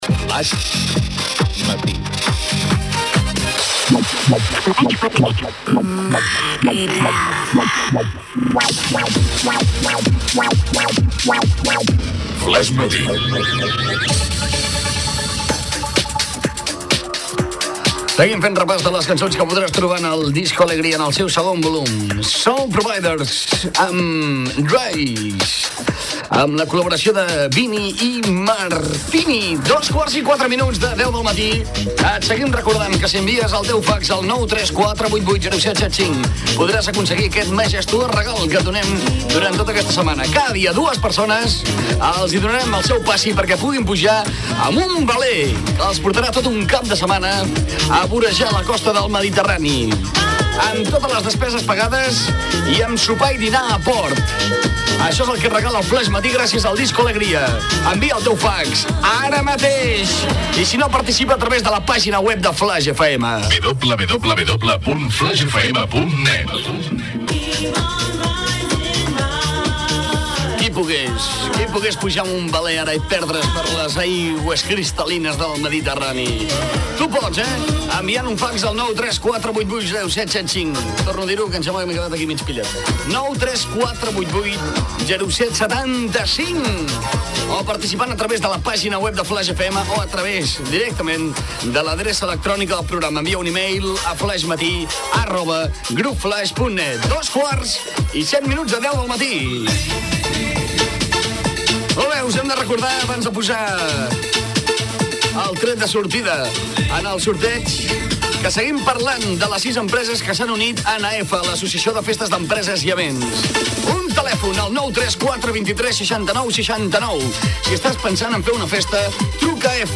Indicatiu del programa, hora, regal, adreça d'Internet, hora, publicitat, tema musical, publicitat, tema musical, trucada a l'oïdor que guanya el premi de fer un viatge en veler
Entreteniment